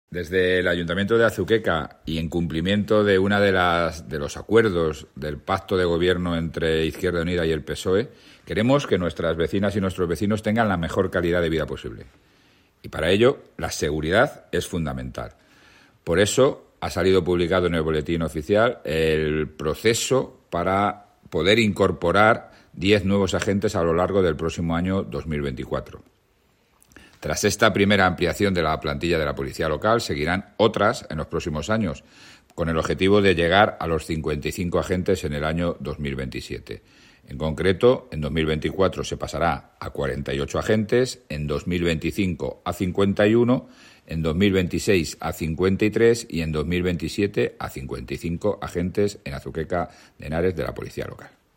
Declaraciones del alcalde José Luis Blanco